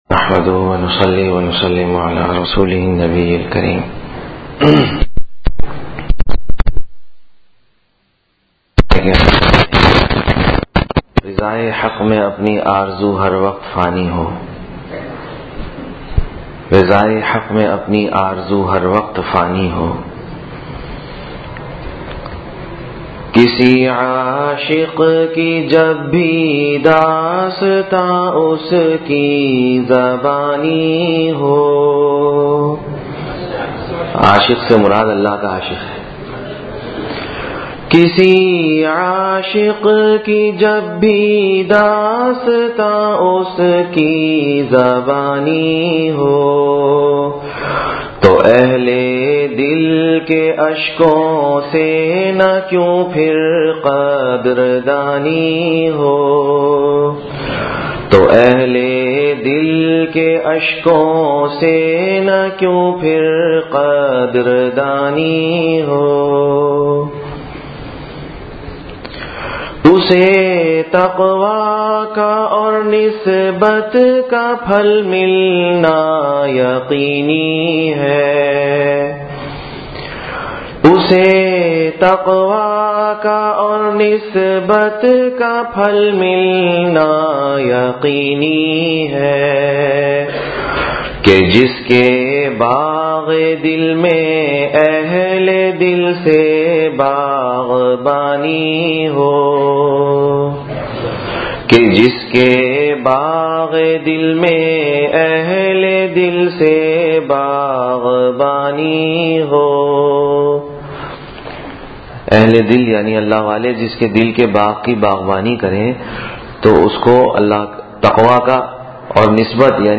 18-Jan-2015 Sunday Bayan